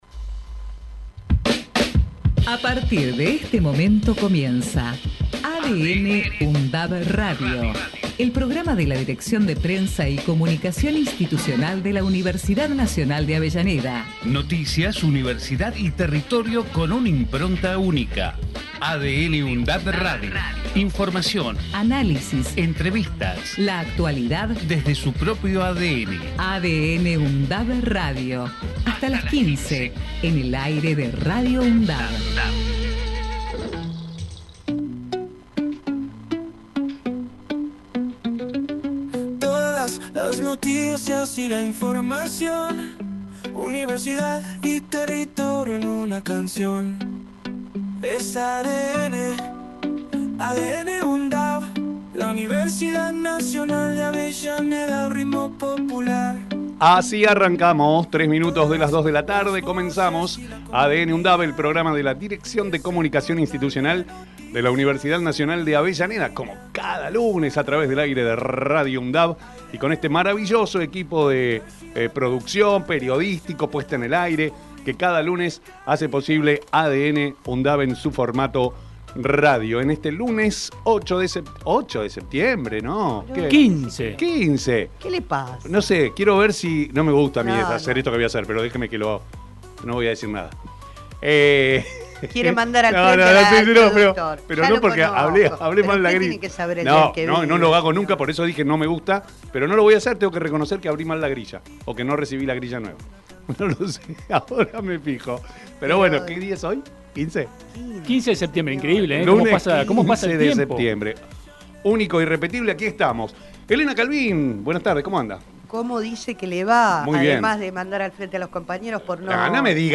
ADN|UNDAV – Radio tiene la vocación de ser un aporte en tal sentido, a través de secciones como “Temas de la Uni”, “Entrevistas”, “Lo que pasa” y “En comunidad”.